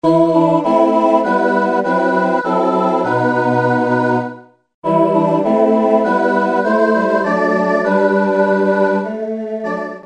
Chor-SATB/Oberstimme/Tasteninstr.